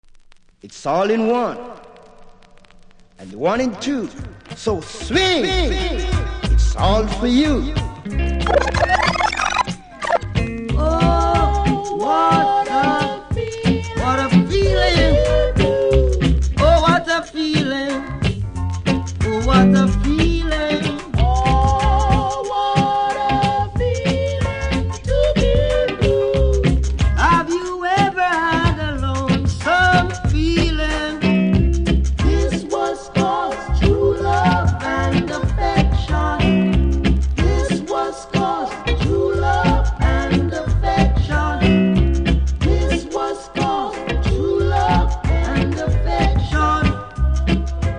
両面ボブの名曲メドレー♪
キズは両面多めですが音にはそれほど影響されていないので試聴で確認下さい。